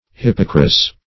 Search Result for " hippocras" : The Collaborative International Dictionary of English v.0.48: Hippocras \Hip"po*cras\, n. [F. hippocras, hypocras, NL. vinum hippocraticum, lit., wine of Hippocrates.]